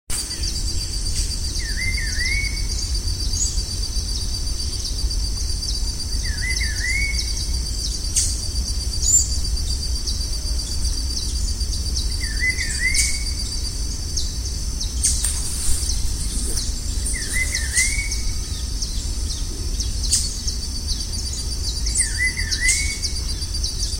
Pitiguari (Cyclarhis gujanensis)
Nome em Inglês: Rufous-browed Peppershrike
Detalhada localização: Reserva Natural Puertos
Condição: Selvagem
Certeza: Gravado Vocal